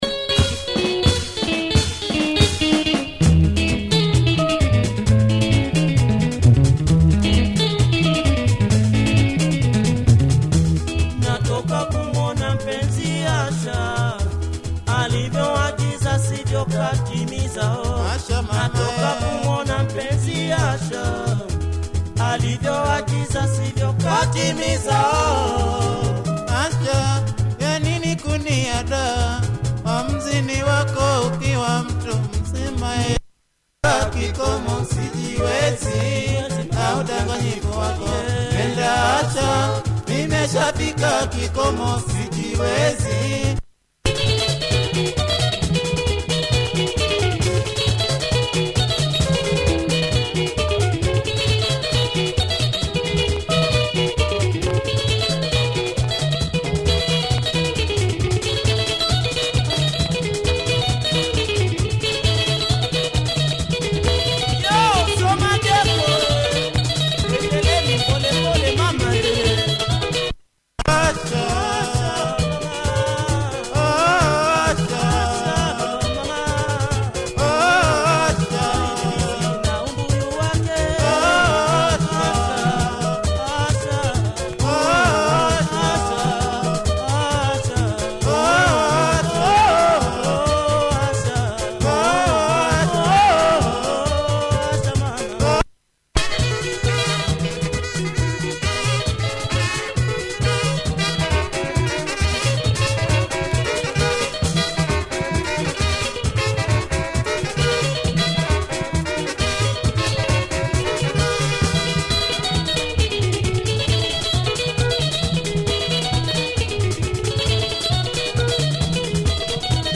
Nice song by this group, played in hard cavacha style!